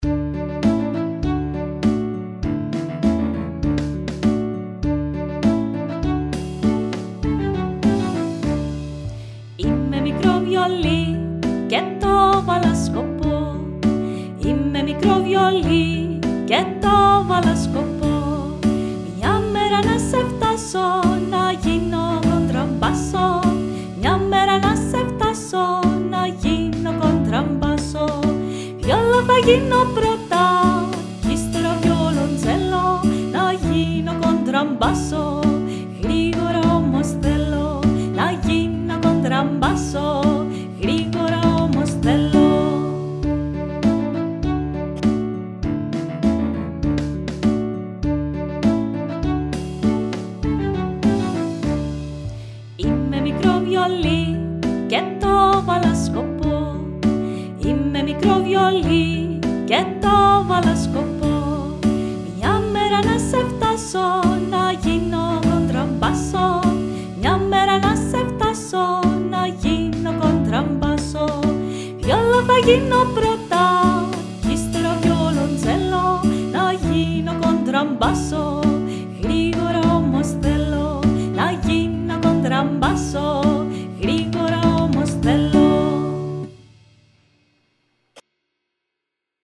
ΒΙΟΛΙ - ΚΟΝΤΡΑΜΠΑΣΣΟ
_data_30_violi_contrabasso.mp3